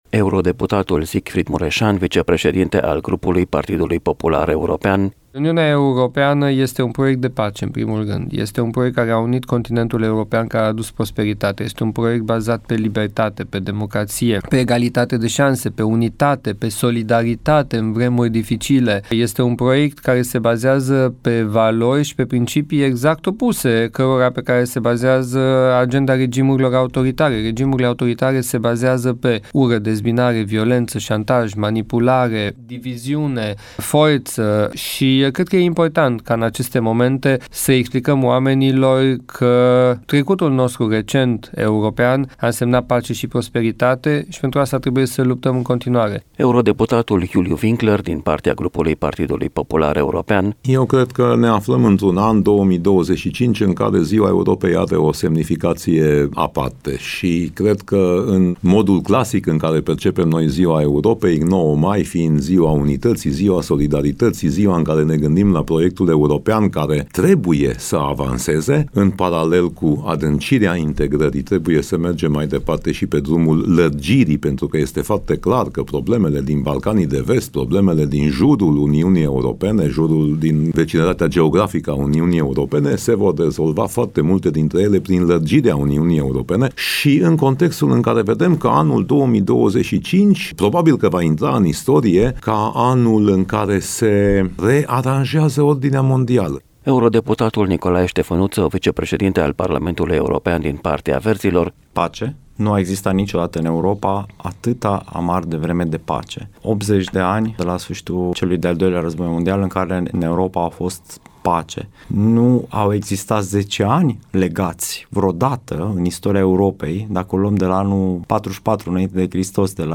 Eurodeputații din vestul României vorbesc despre semnificația actuală a acestui proiect de pace, solidaritate și unitate, într-un context global tensionat și plin de provocări.